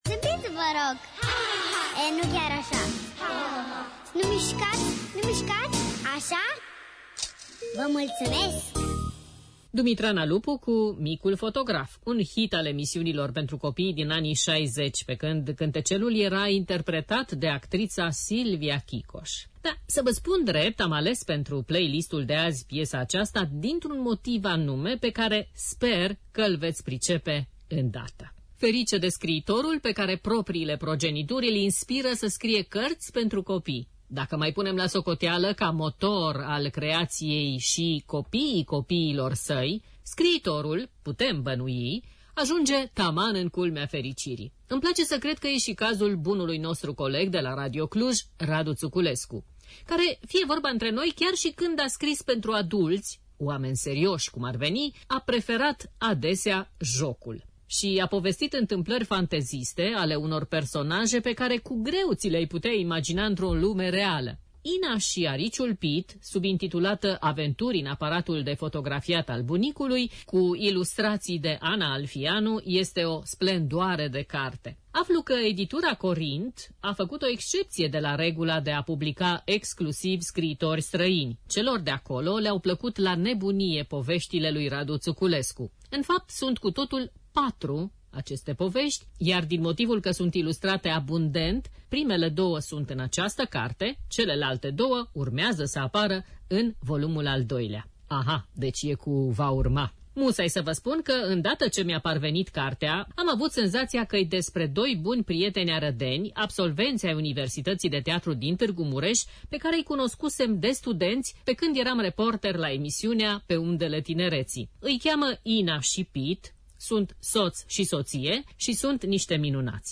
A și prezentat-o în emisiunea „Weekend împreună” din 6 mai 2018.